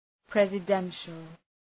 Προφορά
{,prezə’dentʃəl} (Επίθετο) ● προεδρικός